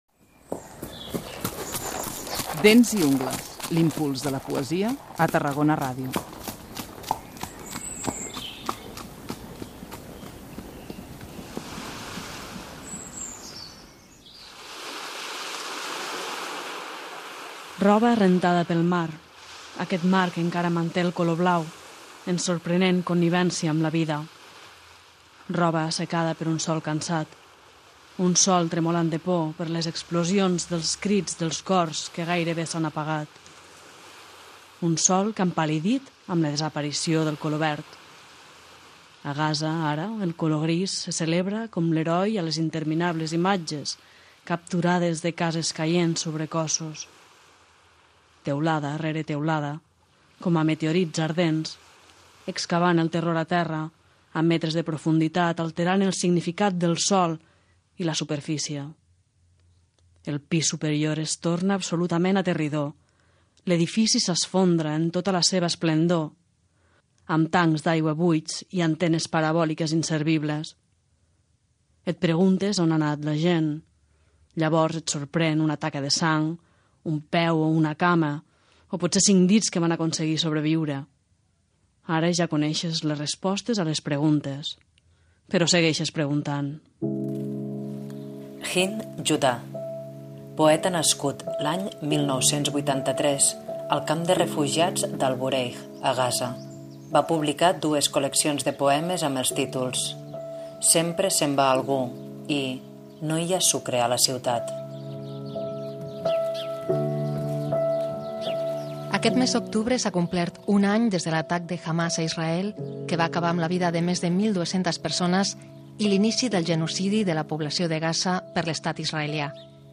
Iniciem temporada amb el pensament i el cor en la població de Gaza. A Dents i ungles posem veu a textos que poetes i periodistes palestins han escrit des de la Franja de Gaza durant l’últim any, recollits a la web Passages through genocide, i altres poetes i artistes d’origen palestí que estan creant arreu del món.